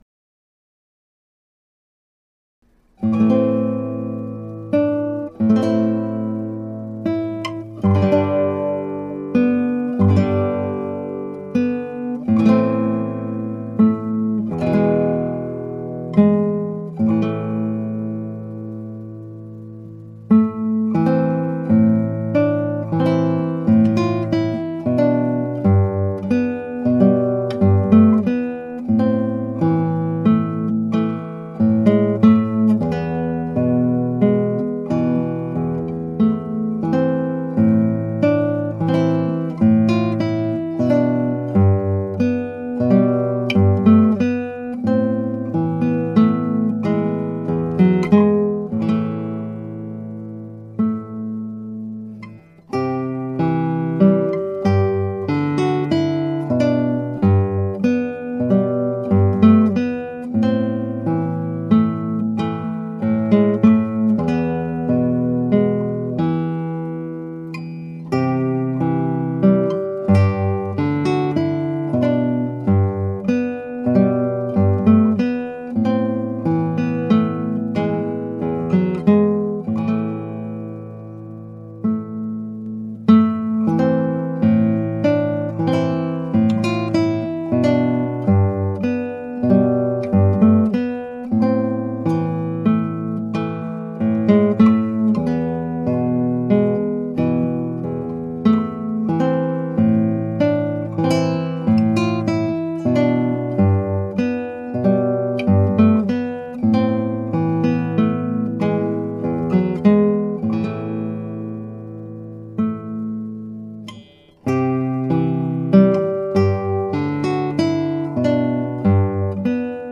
(アマチュアのクラシックギター演奏です [Guitar amatuer play] )
作曲者 イングランド民謡
弦を張り替えましたので再録しました。
音の強弱がばらついていてバランスが悪い仕上がりです。
しばらく弦を張替えていないので特に低音が曇り気味になってしまった。